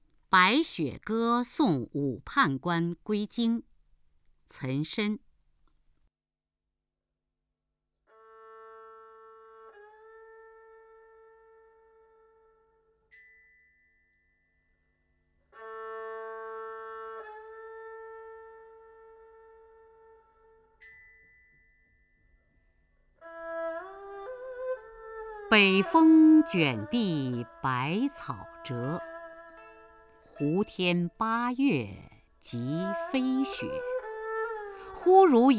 雅坤朗诵：《白雪歌送武判官归京》(（唐）岑参)
名家朗诵欣赏 雅坤 目录